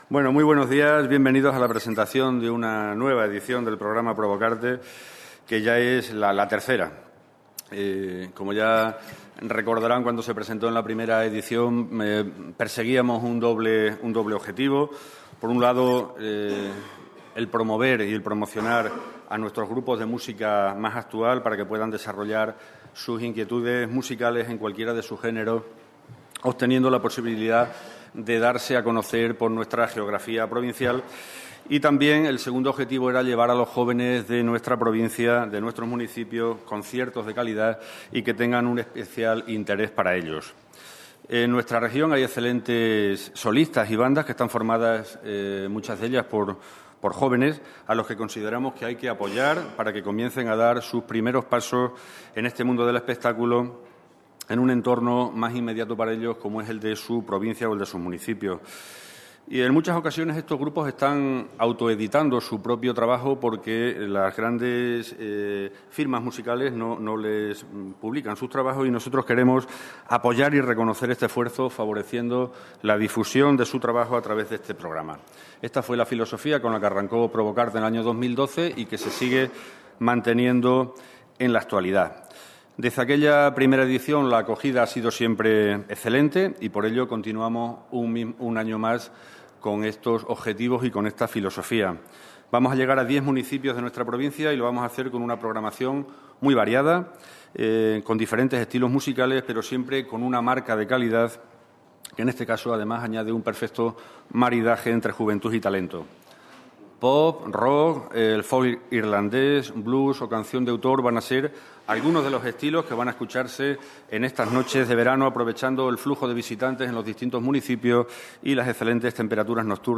CORTES DE VOZ